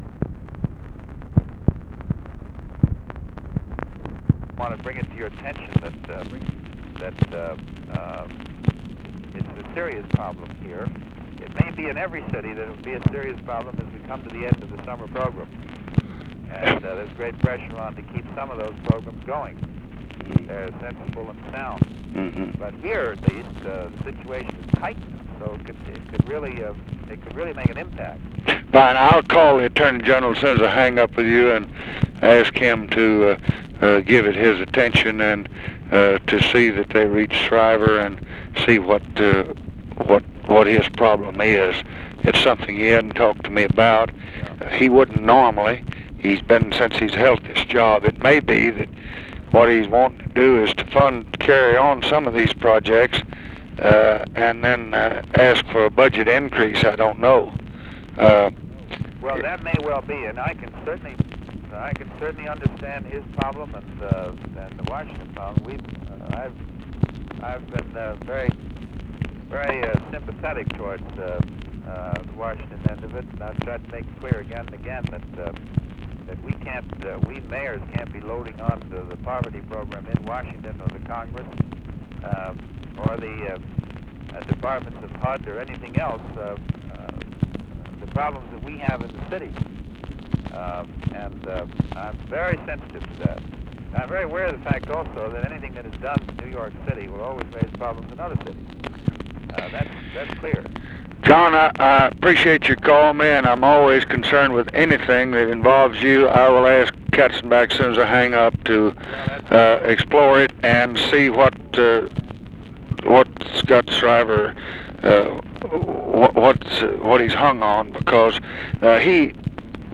Conversation with JOHN LINDSAY, September 2, 1966
Secret White House Tapes